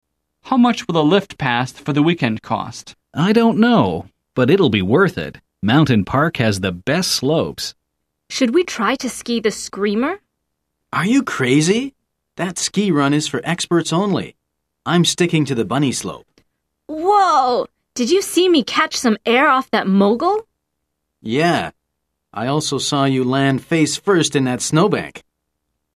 來聽老美怎麼說？